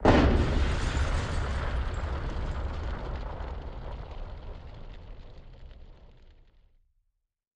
Single explosion with fire.